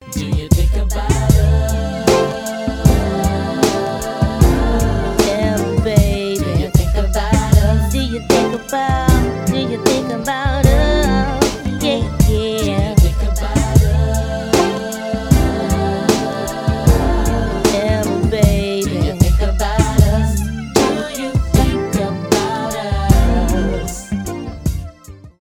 rnb
соул